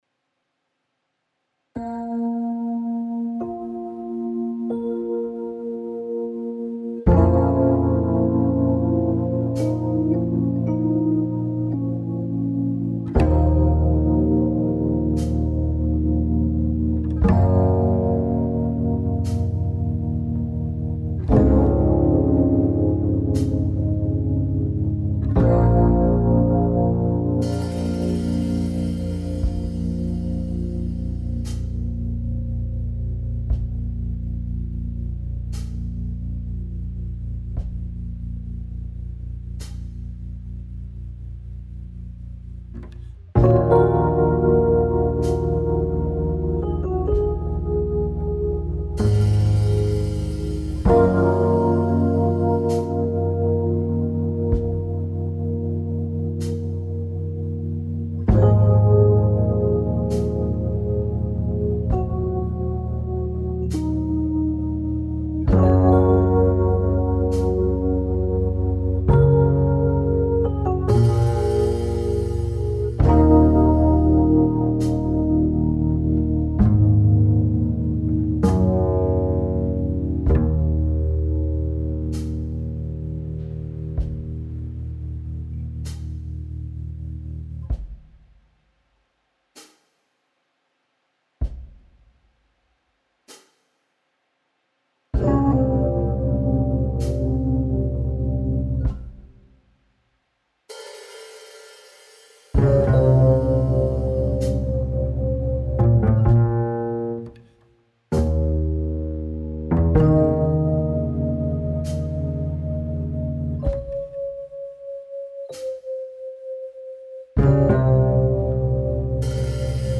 Dark jazz. Slow tempo.